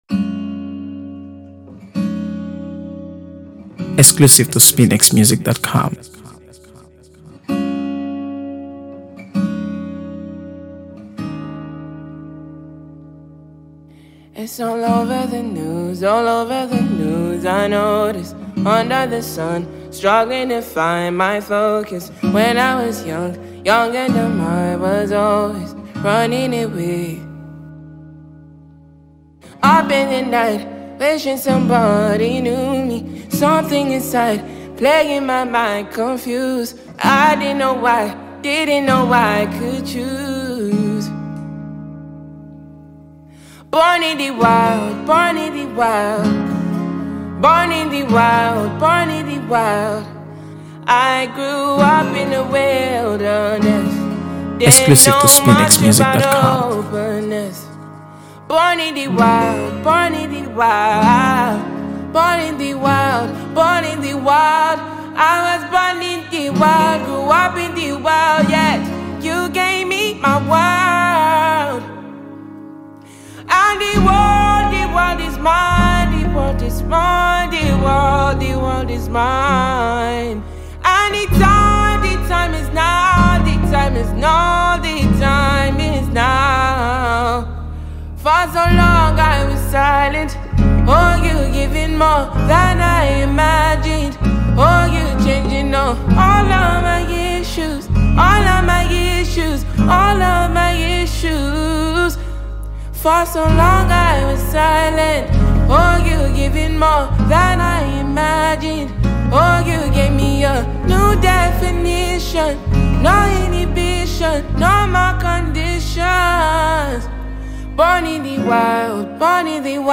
AfroBeats | AfroBeats songs
Nigerian Grammy-winning singer and songwriter
soulful voice